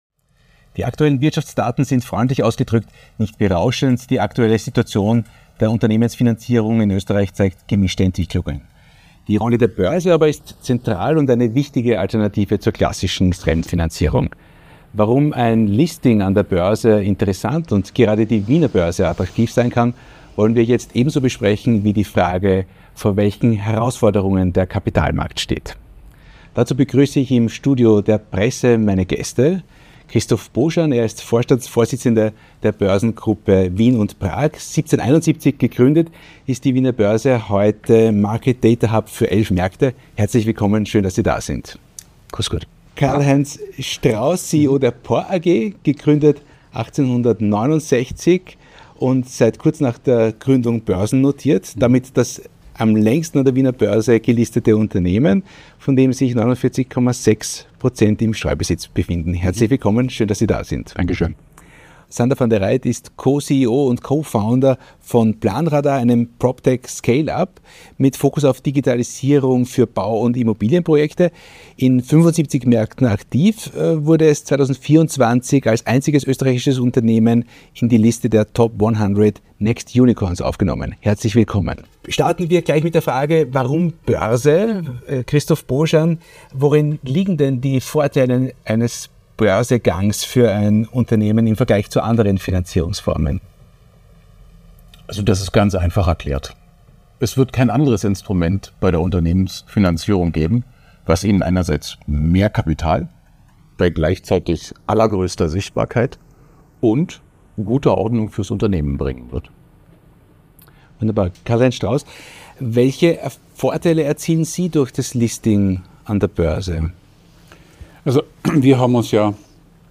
Zum Abschluss der Diskussion wurde der Kapitalmarkt in Europa thematisiert und erörtert, was es braucht, um diesen zu stärken. Unsere Expertenrunde diskutiert im Rahmen des Future Forums in Kooperation mit Die Presse die Vorteile und Herausforderungen des Going und Being Publics.
Dieser Roundtable fand am 20. November 2024 im Studio von Die Presse statt.